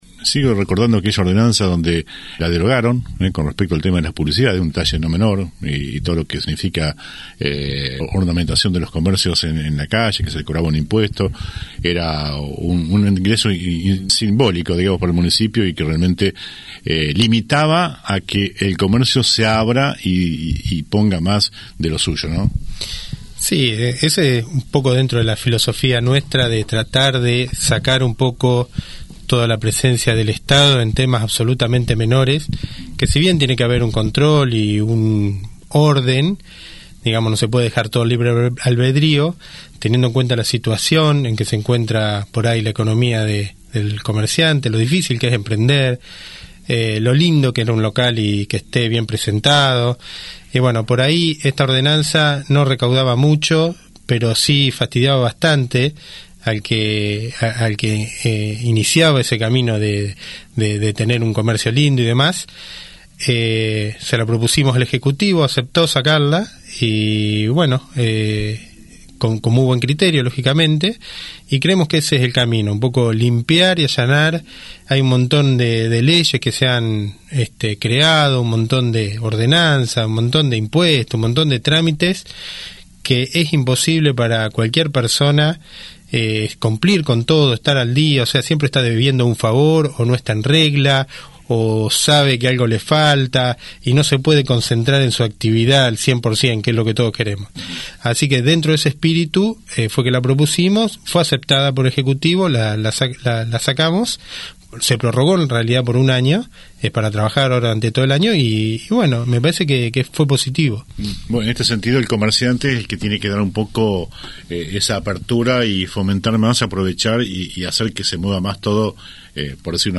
El concejal libertario estuvo en los estudios de LA RADIO tocando diversos temas ya tratados y que planteará en las parlamentarias.